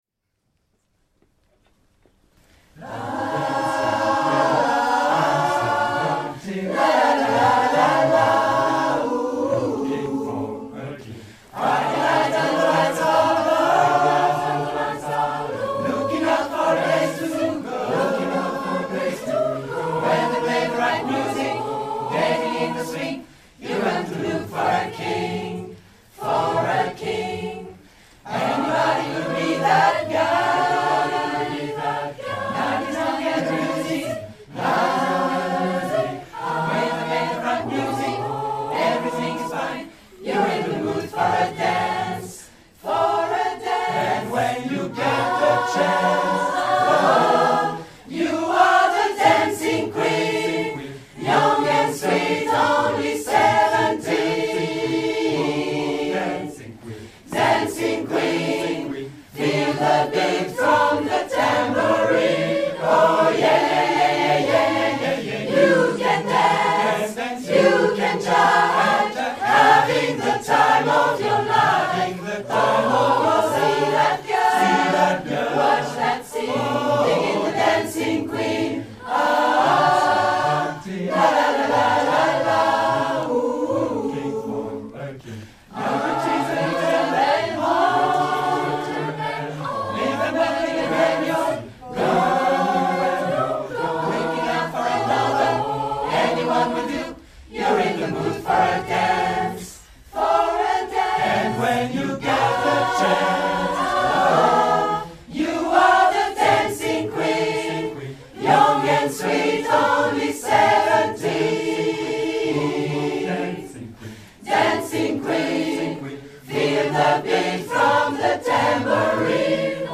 HISTORIQUE DES CHANTS HARMONISES & INTERPRETES DEPUIS 2005